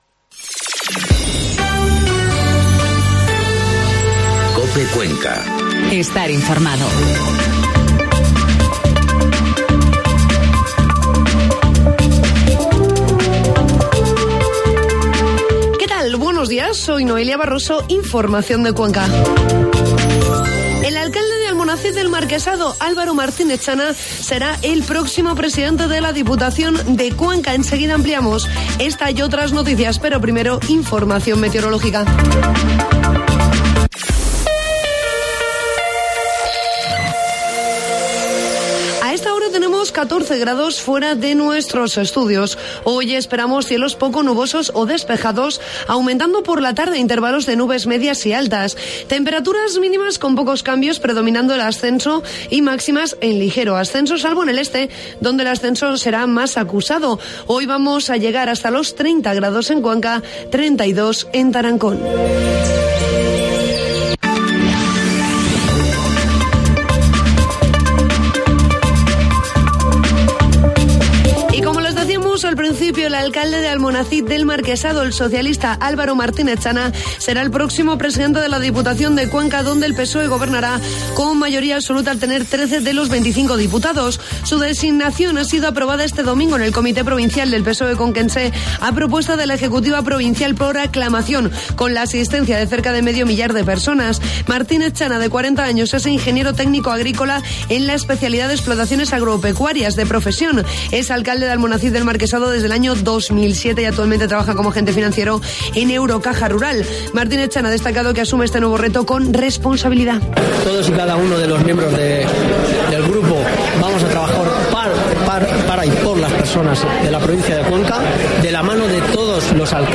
Informativo matinal COPE Cuenca 17 de junio